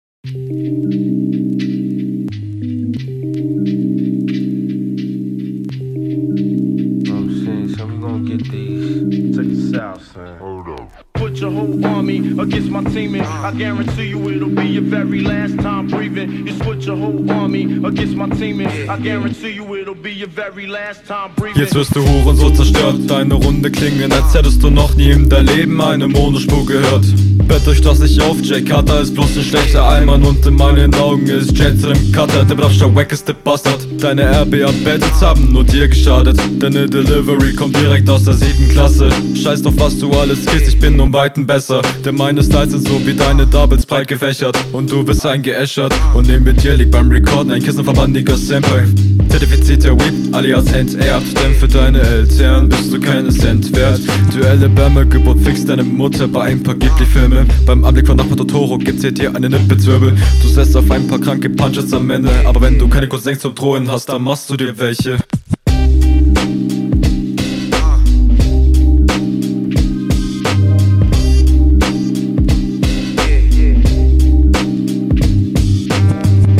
Mir gefällt an der Runde vor allem der Flow, weil er sehr abwechslungsreich gestaltet ist.